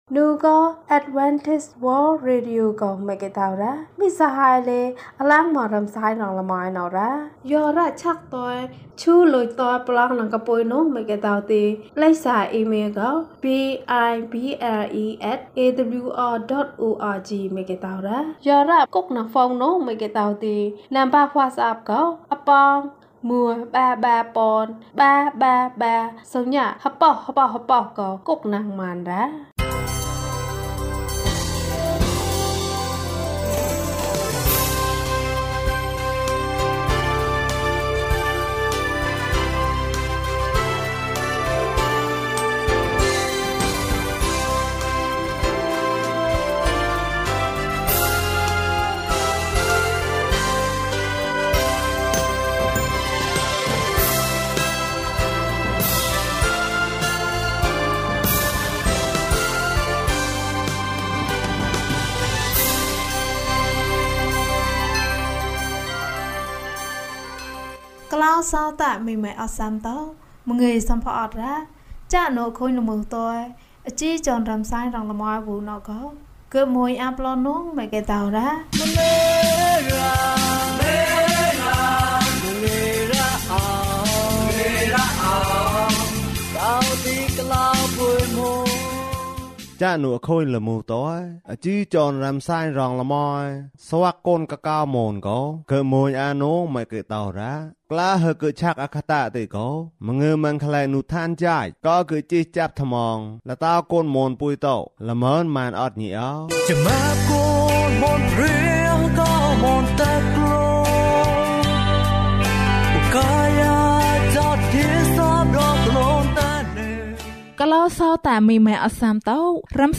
ငါ သူ့ကို သွားတွေ့မယ်။ ကျန်းမာခြင်းအကြောင်းအရာ။ ဓမ္မသီချင်း။ တရားဒေသနာ။